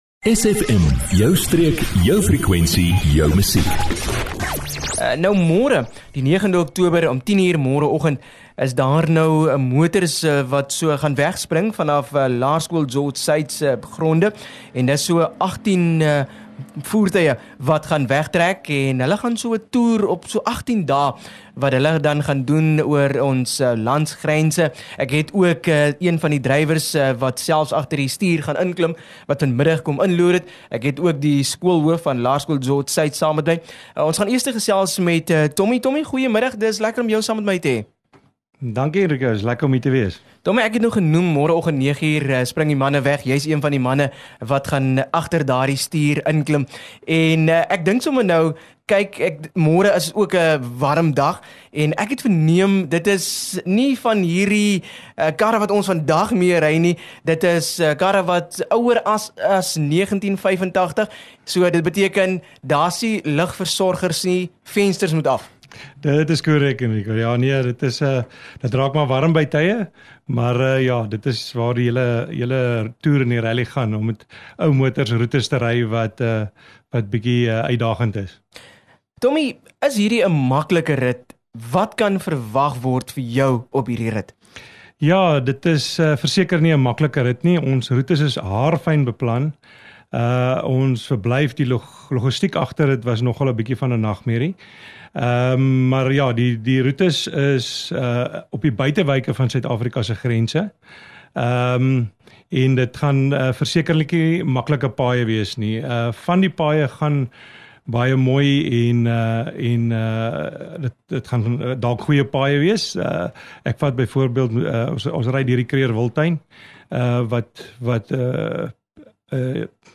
9 Oct Laerskool George Suid Rally - Nuts to Bolt - onderhoud 08 Oktober 2024